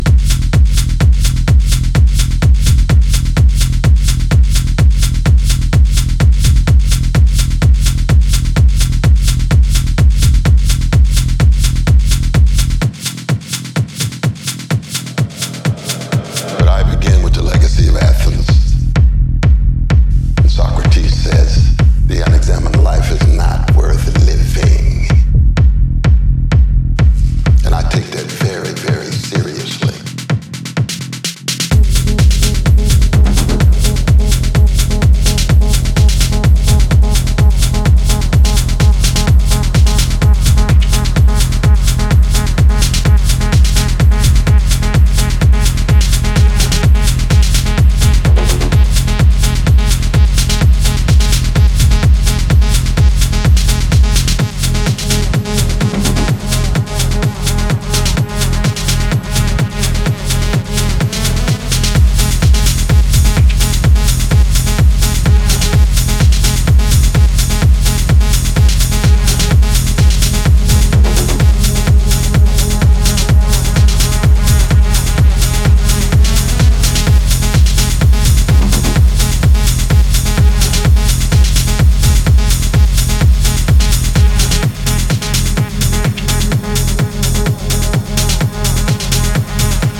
Cosmic Techno
dystopian sci-fi soundtrack written for optimists
While still firmly rooted in the techno genre